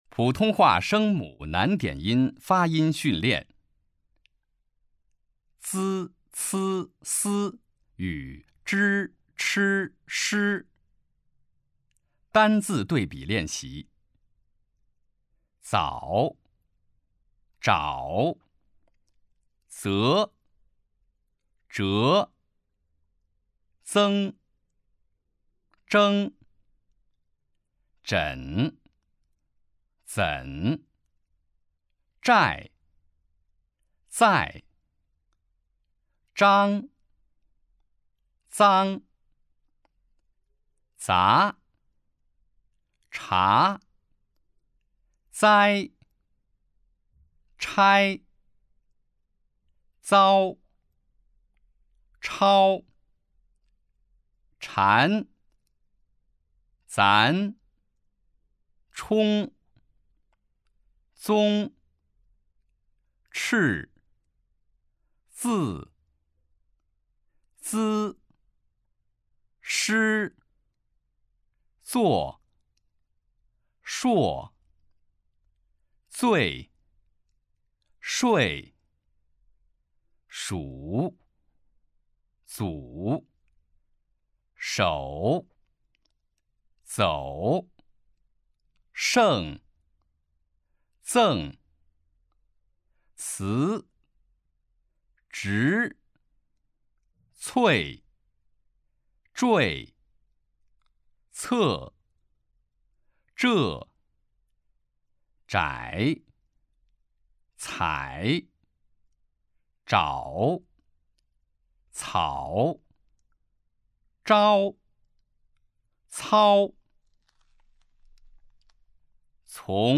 普通话水平测试 > 普通话水平测试资料包 > 02-普通话水平测试提升指导及训练音频
002号普通话声母难点音发音训练.mp3